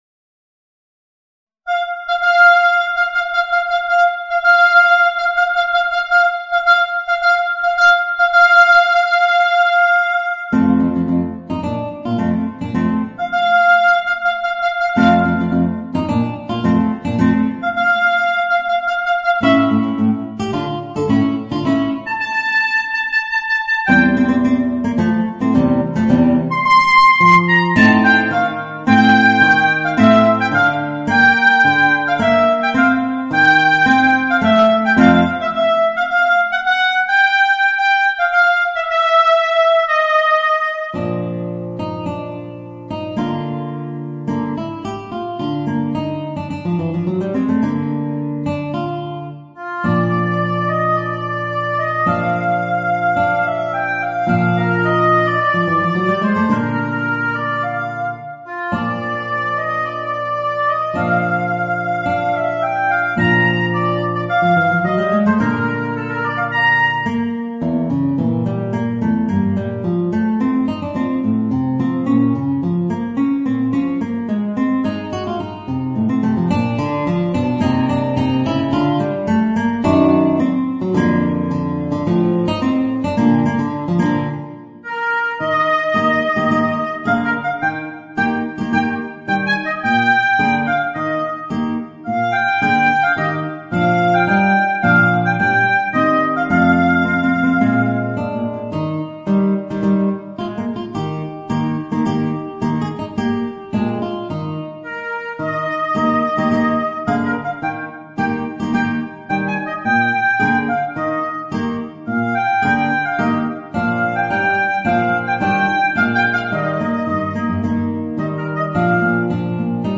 Voicing: Guitar and Oboe